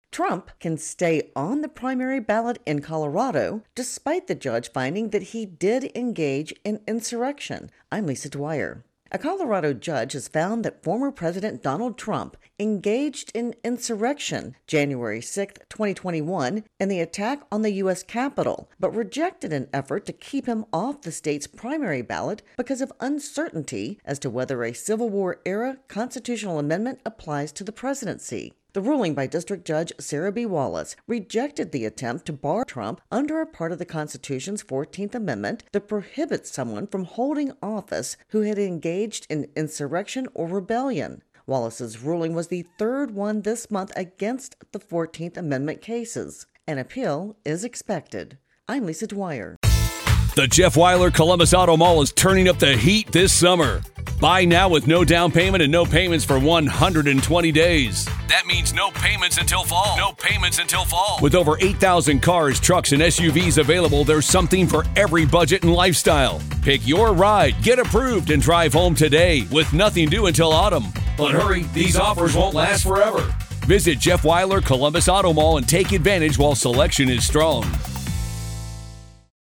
reports on Election 2024 Trump Insurrection Amendment.